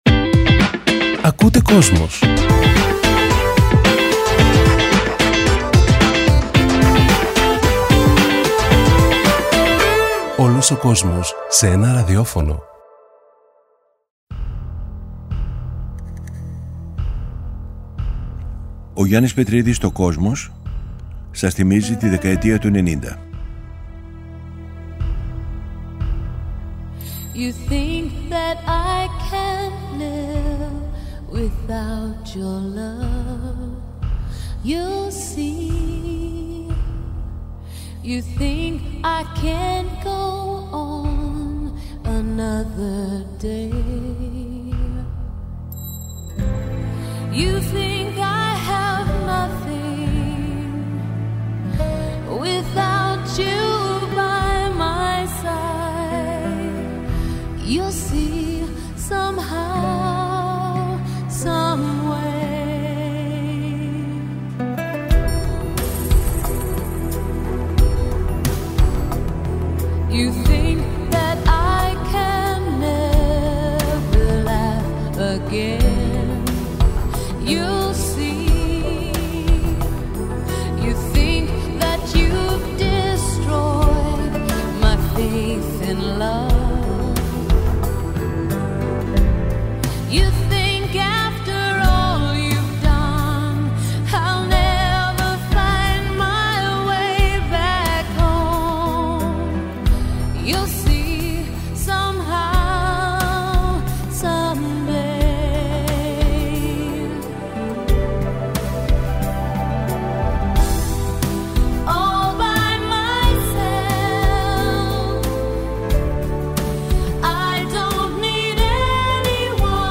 Μετά την ολοκλήρωση του αφιερώματος στη μουσική του 21ου αιώνα, ο Γιάννης Πετρίδης παρουσιάζει ένα νέο μουσικό αφιέρωμα στην 20ετία 1980-2000, από την Κυριακή 14 Μαρτίου 2021 και κάθε Κυριακή στις 19:00 στο Kosmos 93.6. Παρουσιάζονται, το ξεκίνημα της rap, η μεταμόρφωση του punk σε new wave, οι νεορομαντικοί μουσικοί στην Αγγλία, καθώς και οι γυναίκες της pop στην Αμερική που άλλαξαν τη δισκογραφία.